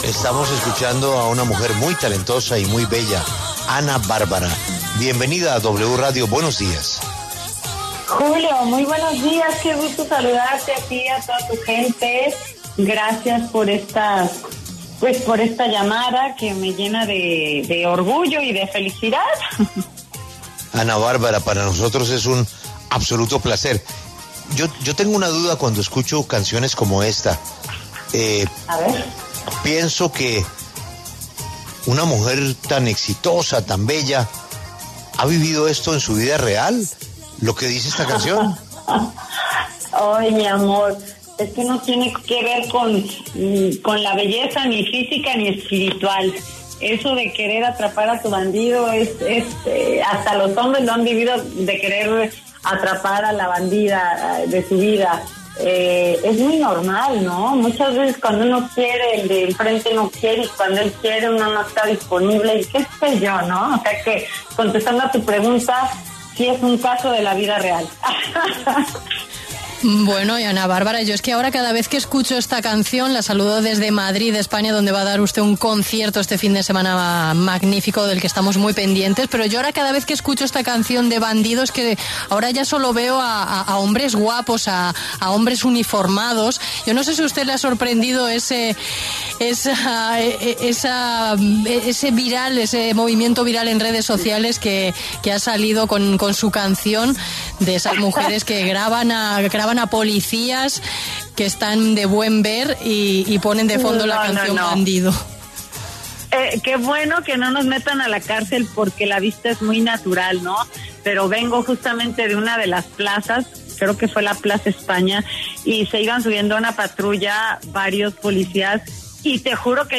La cantante mexicana aseguró en La W que gracias a la tecnología y las redes sociales su canción ‘Bandida’ se convirtió en un tendencia mundial.